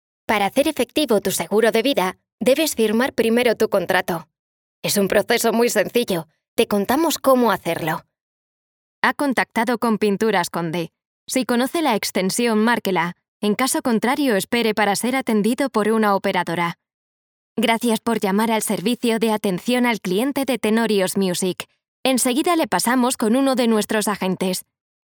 Young, Urban, Cool, Reliable, Natural
Telephony